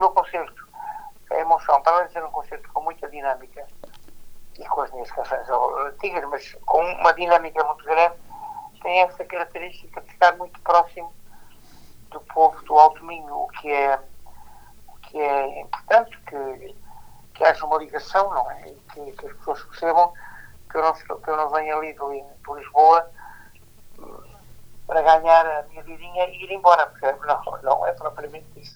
José Cid garantiu esta quarta-feira à Rádio Vale do Minho que nunca se esqueceu da última vez que passou por Monção.